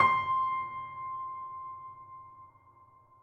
piano-sounds-dev
c5.mp3